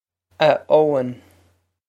Ah Oh-in
This is an approximate phonetic pronunciation of the phrase.